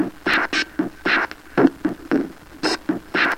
Tag: 工业 循环 混音 RNO